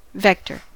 vector: Wikimedia Commons US English Pronunciations
En-us-vector.WAV